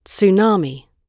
Tsunami /tsuːˈnɑːmi/
tsunami__gb_2.mp3